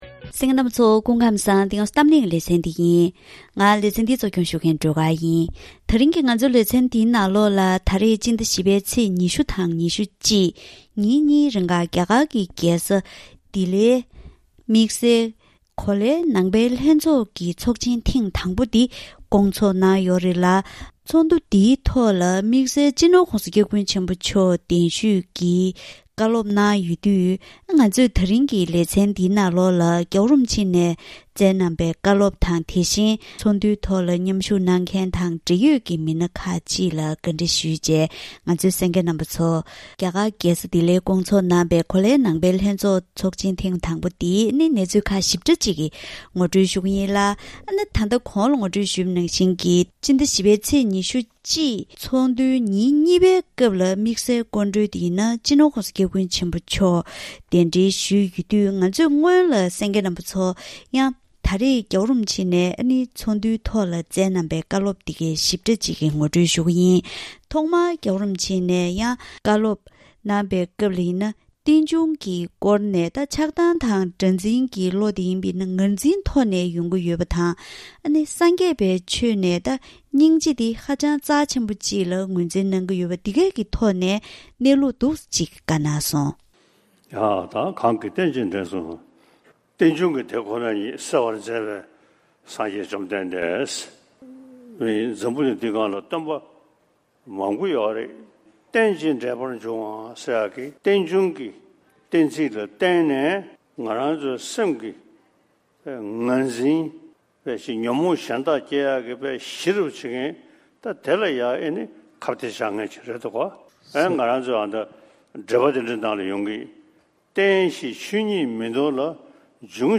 ད་རིང་གི་གཏམ་གླེང་ཞལ་པར་ལེ་ཚན་ནང་རྒྱ་གར་གྱི་རྒྱལ་ས་ལྡི་ལིར་གོ་ལའི་ནང་པའི་ལྷན་ཚོགས་ཐོག་མ་འདི་སྐོང་ཚོགས་གནང་ཡོད་པ་དང་། ཚོགས་འདུ་ཉིན་གཉིས་པར་སྤྱི་ནོར་༧གོང་ས་སྐྱབས་མགོན་ཆེན་པོ་མཆོག་དམིགས་བསལ་སྐུ་མགྲོན་དུ་གདན་ཞུས་ཀྱིས་བཀའ་སློབ་གནང་སྐབས་སྙིང་རྗེ་ཆེན་པོ་འདི་ཐོག་མཐའ་བར་གསུམ་དུ་ཧ་ཅང་གལ་ཆེ་བ་དང་། སྡུག་བསྔལ་གྱི་རྩ་བ་ངོ་འཕྲོད་པའི་ཐོག་ནས་བདེན་འཛིན་ཤུགས་ཆུང་རུ་གཏོང་དགོས་སྐོར་སོགས་བཀའ་གནང་ཡོད་པའི་འབྲེལ་ཡོད་སྐོར་བཀའ་མོལ་ཞུས་པ་ཞིག་གསན་རོགས་གནང་།